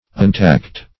Untalked \Un*talked"\, a.